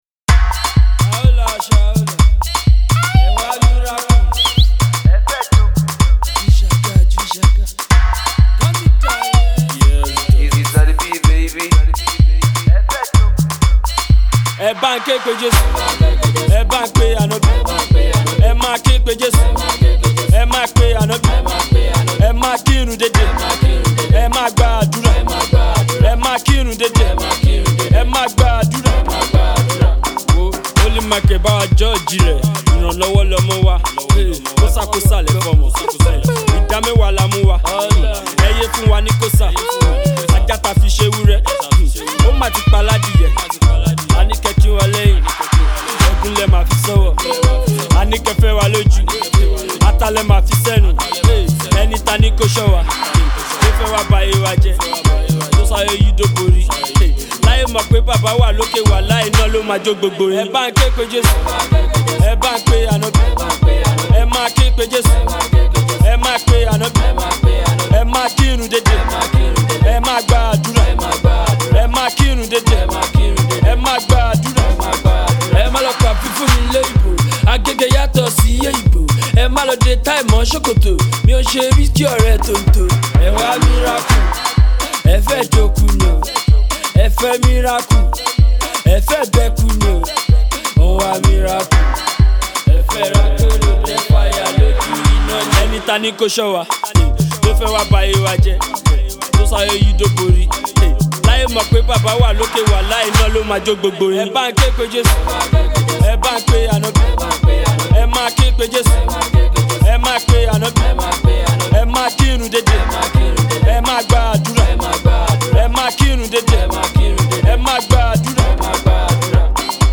Afro-pop
street-pop